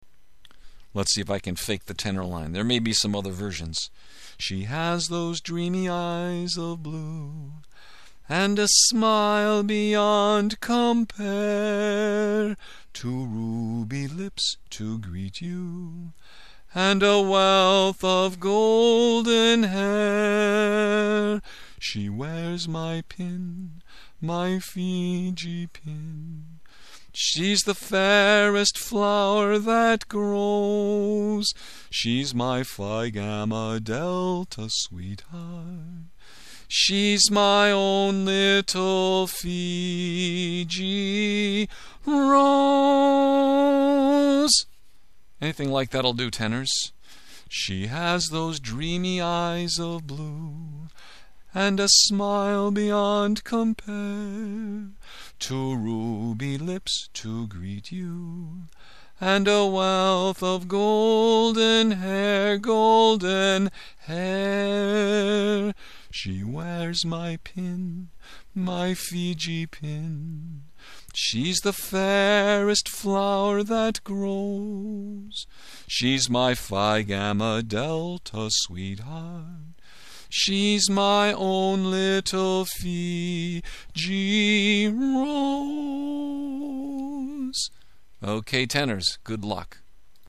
Tenors